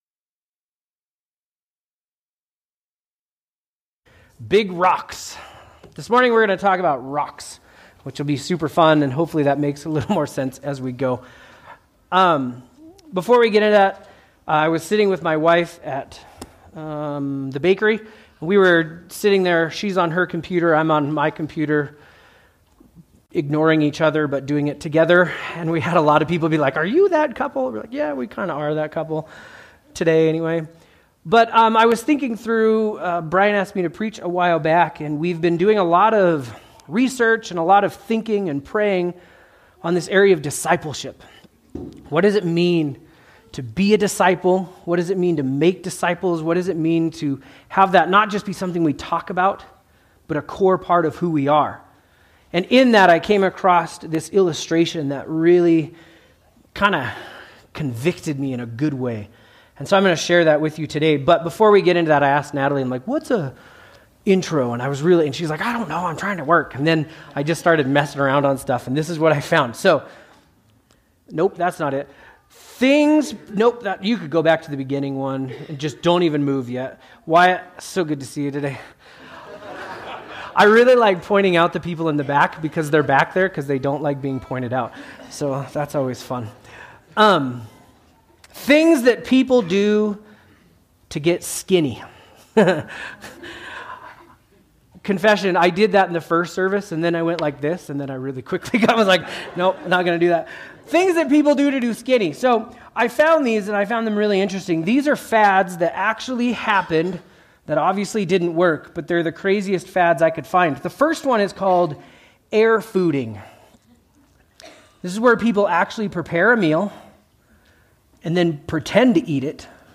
A message from the series "B.L.E.S.S. Rhythms." Today's Message: Encourage Always & Eat Together